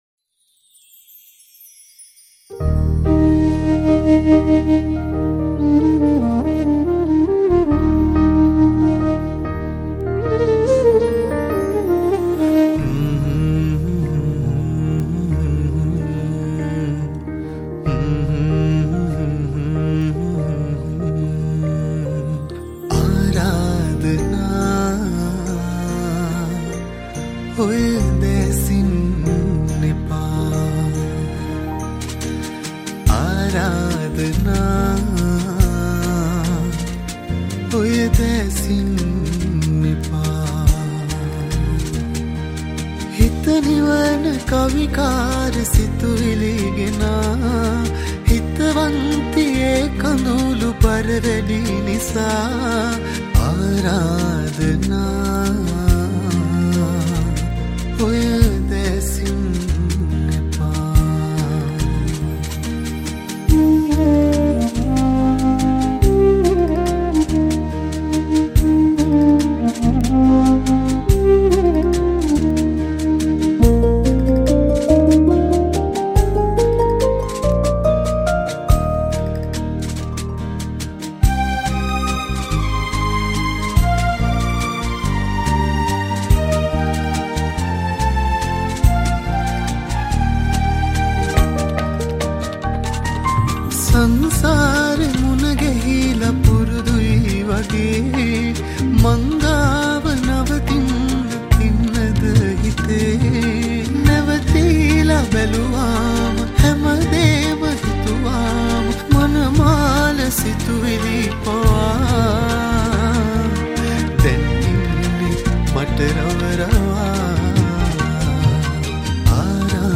Category: Teledrama Song